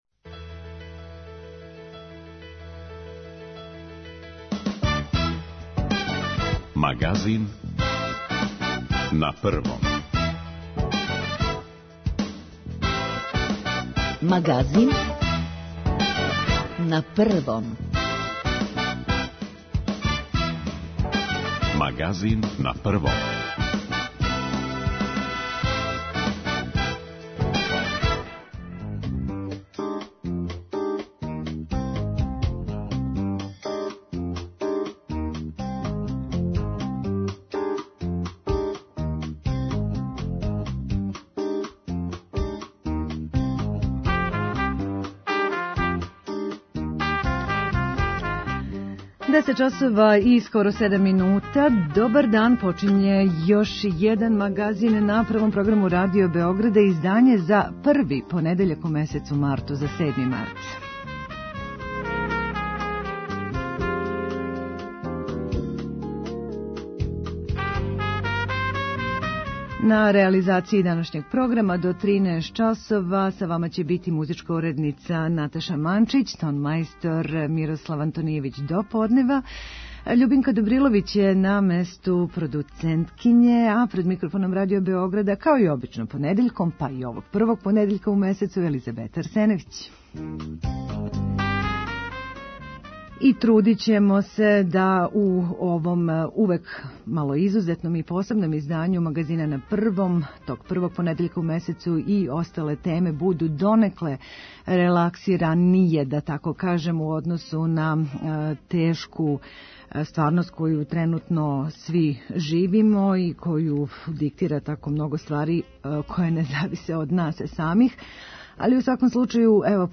И у месецу који најављује, а затим и доноси пролеће, настављамо устаљену праксу и један сат нашег програма одвајамо за укључења слушалаца и њихове сугестије, предлоге и примедбе о нашем програму.